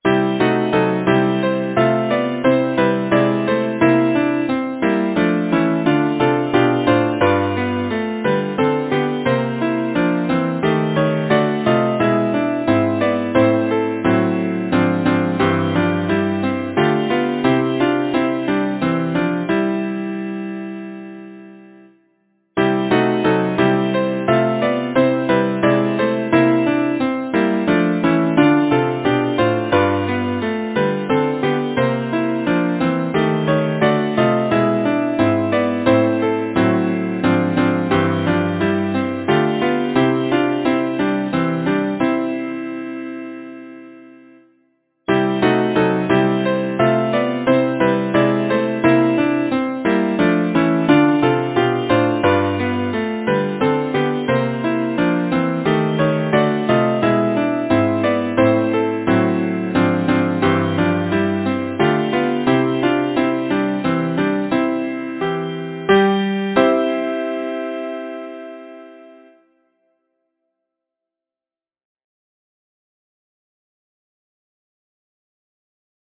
Title: Between Composer: Thomas Adams Lyricist: Constance Morgancreate page Number of voices: 4vv Voicing: SATB Genre: Secular, Partsong
Language: English Instruments: A cappella